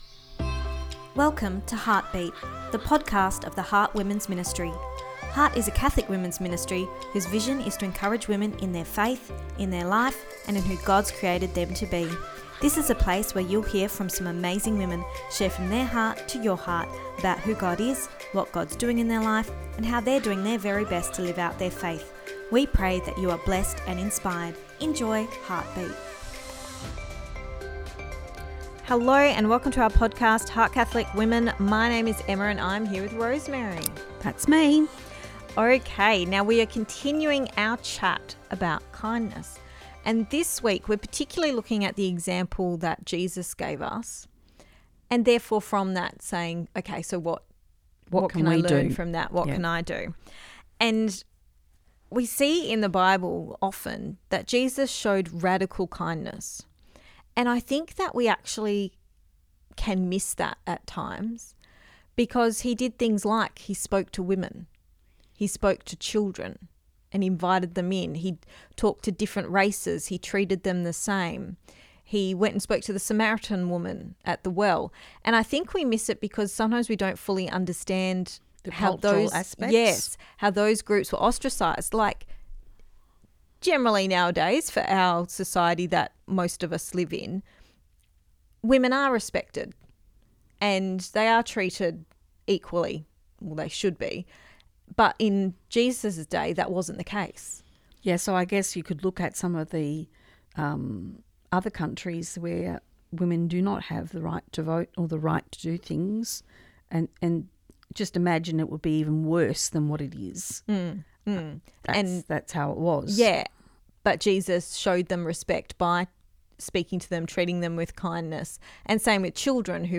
Ep255 Pt2 (Our Chat) – Radical Kindness